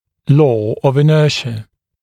[lɔː əv ɪ’nɜːʃə][ло: ов и’нё:шэ]закон инерции